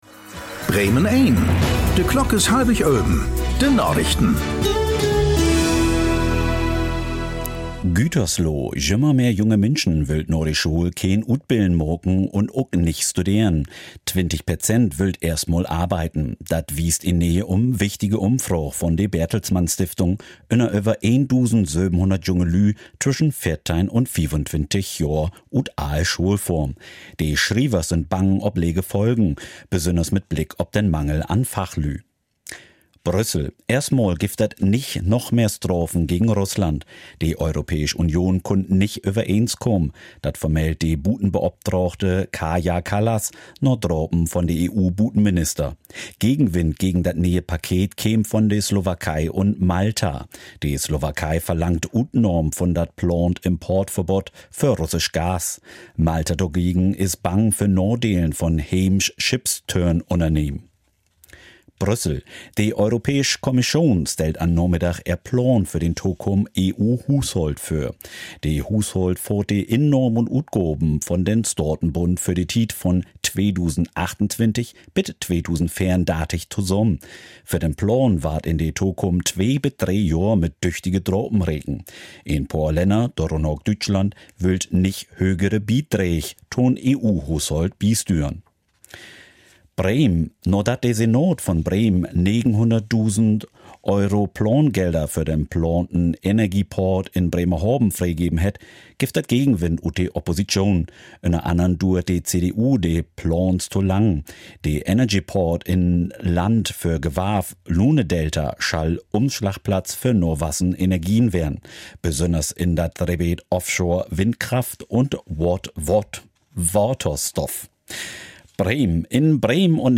Plattdeutsche Nachrichten
Aktuelle plattdeutsche Nachrichten werktags auf Bremen Eins und hier für Sie zum Nachhören.